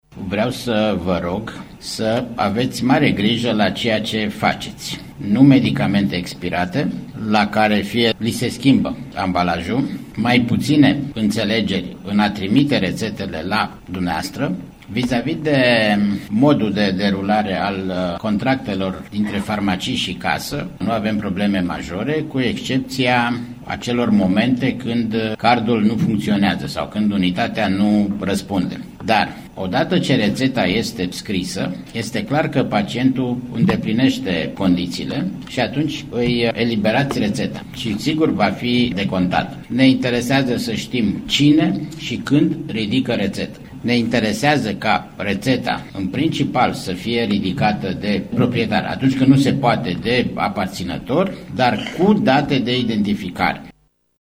Așa a declarat, azi, președintele Casei Naționale de Asigurări de Sănătate, Vasile Ciurchea în deschiderea Conferinței Naționale de Farmacie.